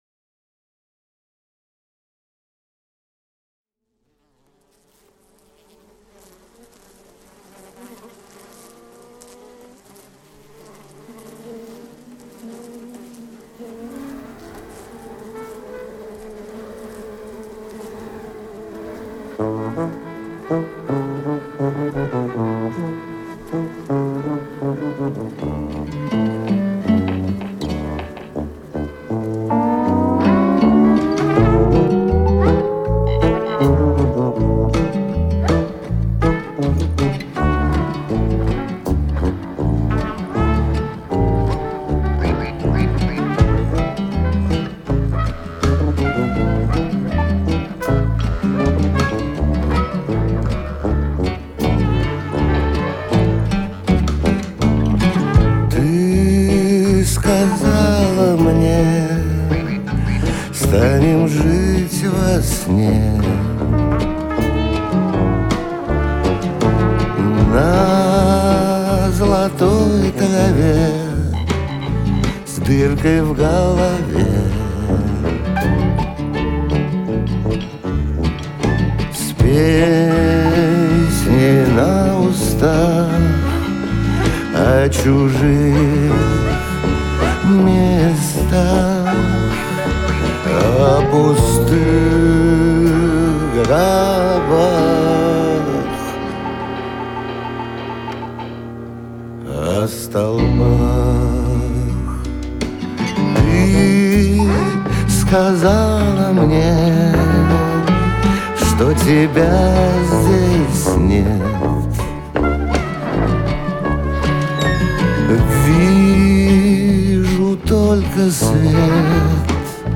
Категории: Русские песни, Рок.